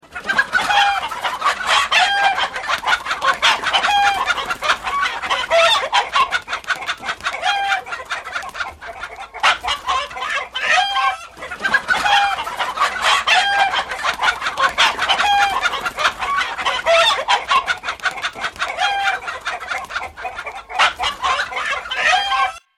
Категория: Различные звуковые реалтоны